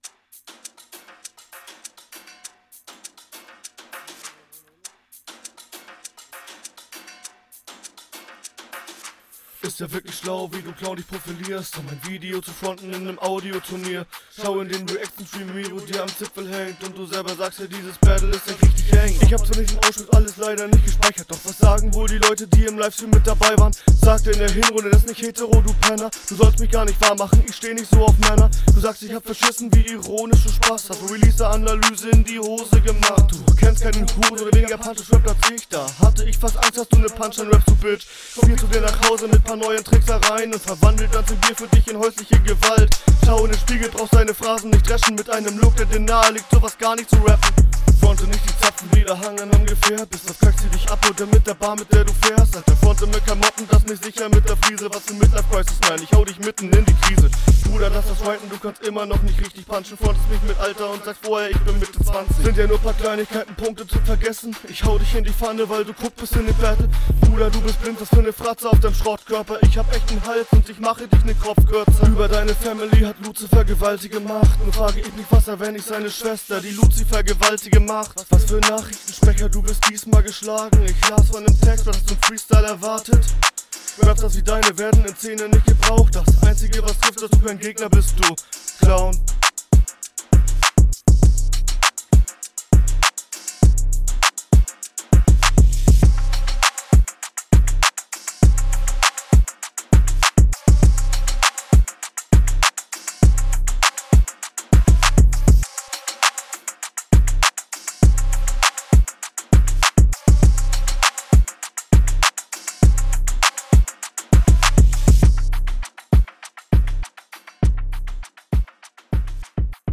flowlich deutlich schlechter als deine hr. shuffles sehr unsauber, der zeilenüberzug bei 1:16 oder so …